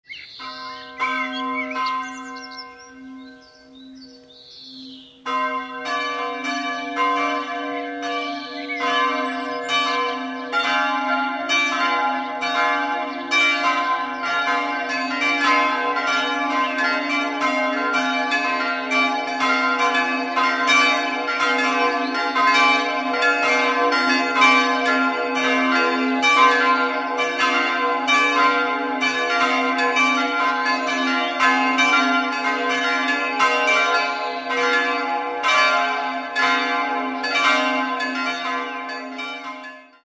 Gebetsglocke c''+0 ??? kg 80 cm 15. Jh. unbekannt Dankesglocke d''+0 209 kg 71 cm 1960 Rudolf Perner, Passau Taufglocke f''+2 127 kg 59,5 cm 1960 Rudolf Perner, Passau Sterbeglocke a''-2 ??? kg 50 cm 16. Jh. unbekannt Youtube-Video mit dem Glockengeläut: